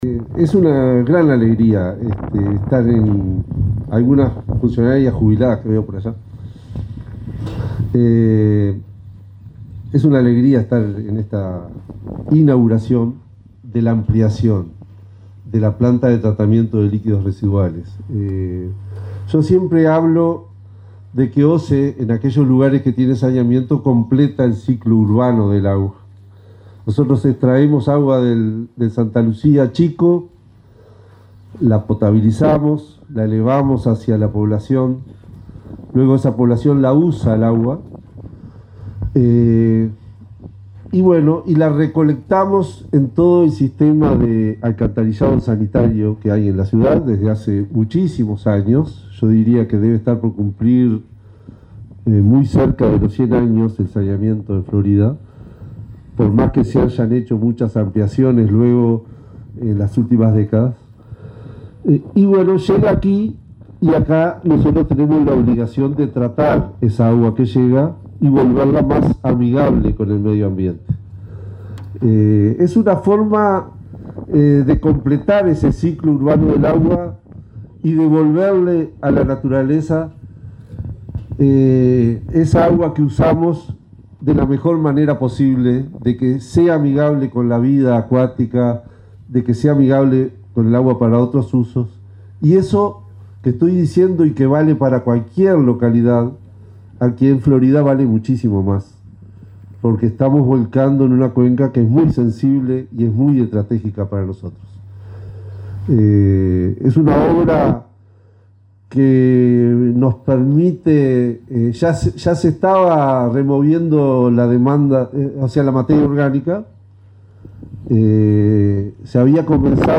Palabras del presidente de OSE, Raúl Montero
El presidente de la OSE, Raúl Montero, fue el orador central en el acto de inauguración de las obras de amplificación y reforma en la planta de